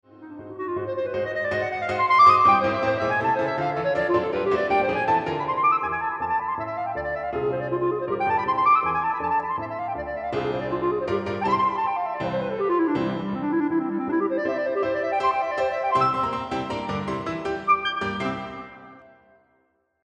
Tchaikovsky-Violin-Concerto-Clarinet-and-Piano-Ending.mp3
transcribed for Clarinet